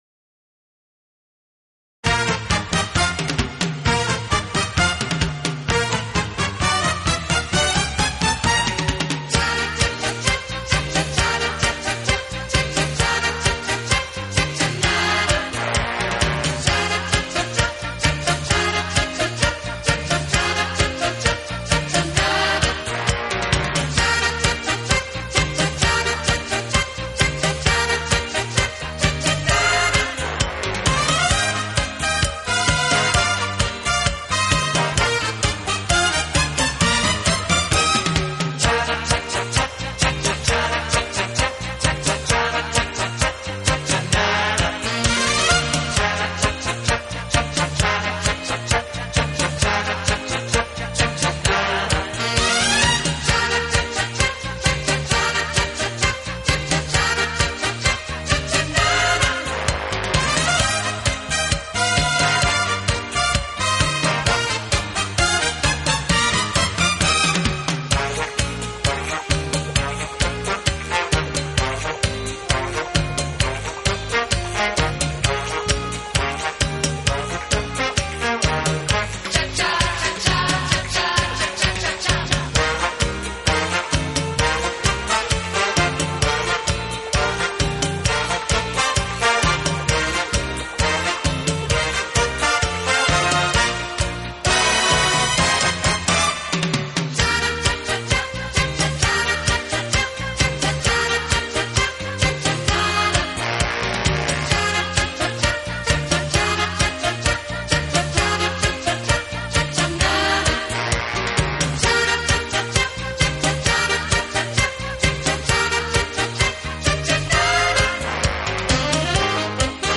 【轻音乐】
有动感，更有层次感；既有激情，更有浪漫。